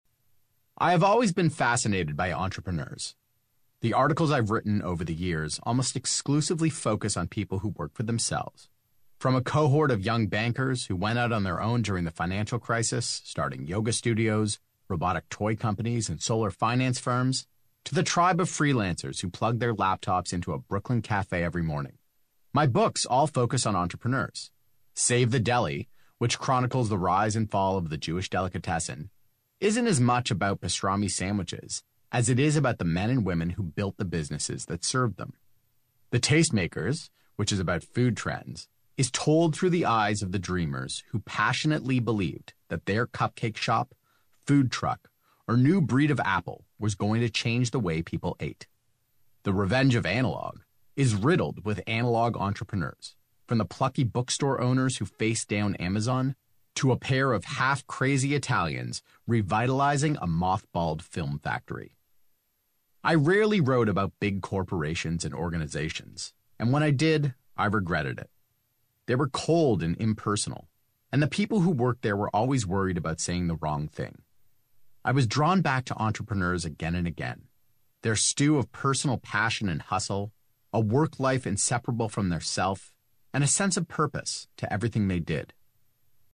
David, a Canadian, sounds like this: